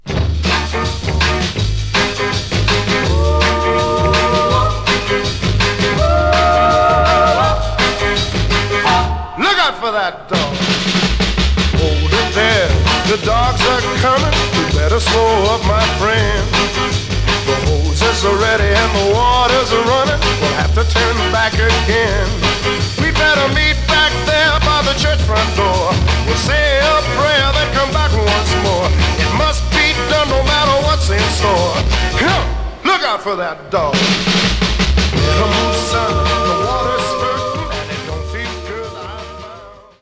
Genre: R&B, Soul/Funk